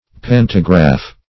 Pantograph \Pan"to*graph\, n. [Panto- + -graph: cf. F.